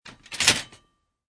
Descarga de Sonidos mp3 Gratis: tabla de planchar 1.